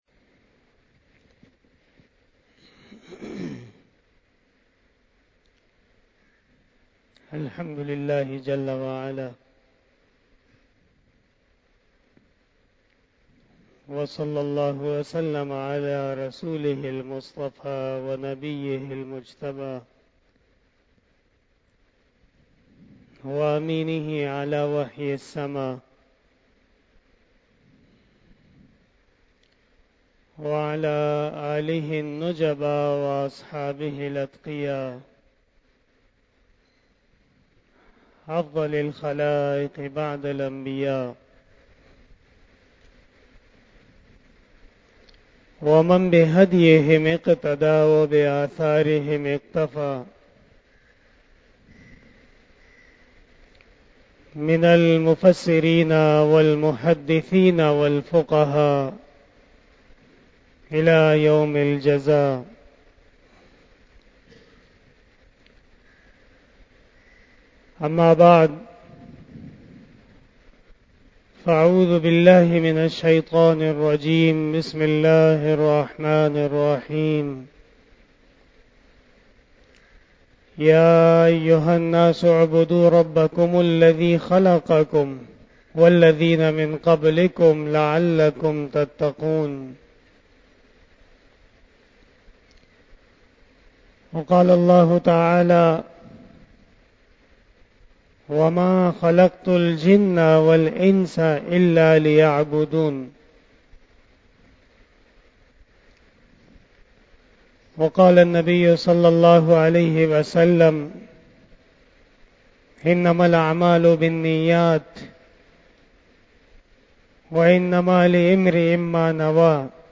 01:37 PM 392 Khitab-e-Jummah 2022 --